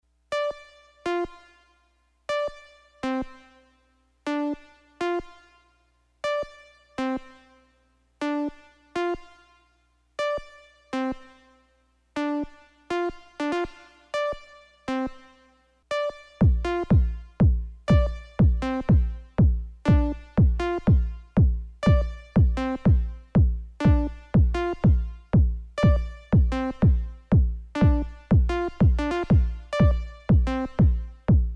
In pattern 3 it’s sounding terrible out of sync.
The first part of this example plays the sample as it should be, recorded directly from the synth sequenced by Octa. The second part is the sample with a kick, playing inside the Octa, already timestretched. You can feel it quickly looses sync with the kick.